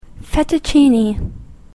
/ˌfɛtʌˈtʃini(米国英語), ˌfetʌˈtʃi:ni:(英国英語)/